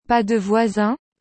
• Dans une phrase interrogative (qui se termine par un point d’interrogation), la voix doit monter à la fin. Exemple : « Pas de voisin ? »